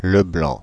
Ääntäminen
Synonyymit visage pâle pied-tendre Ääntäminen Paris Paris Tuntematon aksentti: IPA: /blɑ̃/ Haettu sana löytyi näillä lähdekielillä: ranska Käännös Konteksti Substantiivit 1. white person 2.